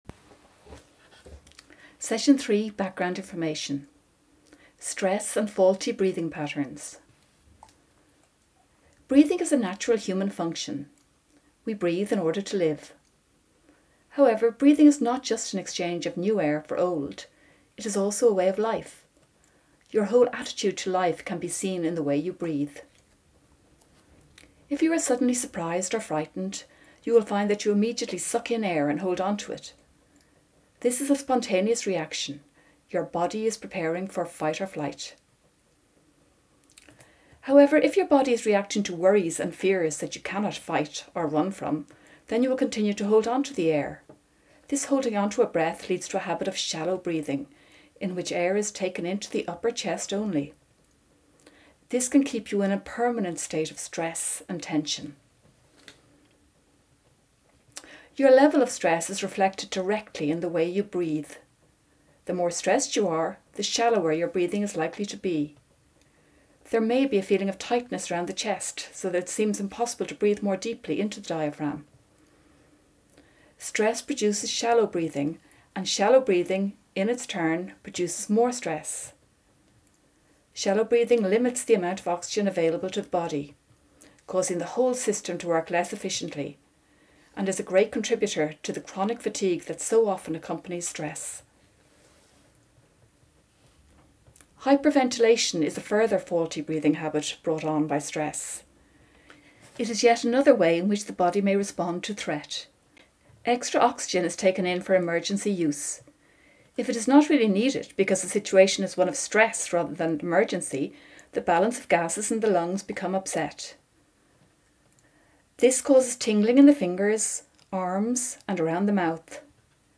SMTP session 3 background lecture